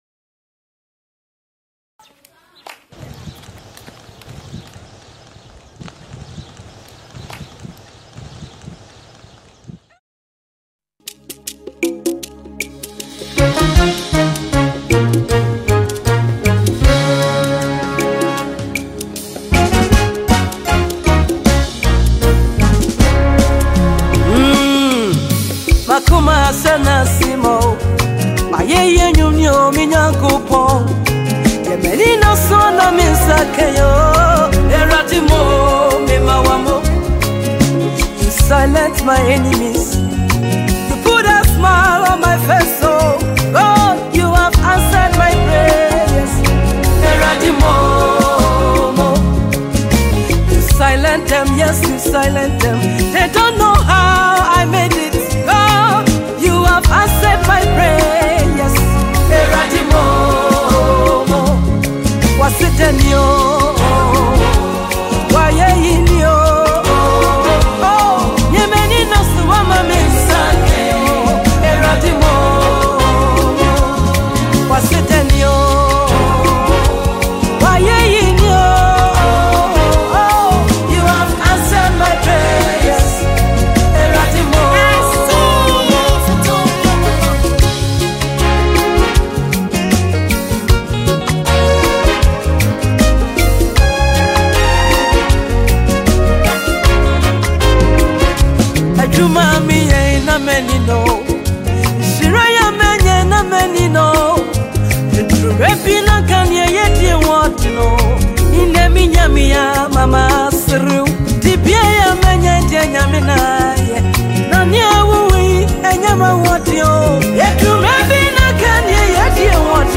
done by prolific and gospel Minister singer
is a heartfelt song of gratitude to the Lord Almighty.